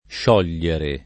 +š0l’l’ere] (poet. sciorre [+š0rre]) v.; sciolgo [+š0lgo], ‑gli — pass. rem. sciolsi [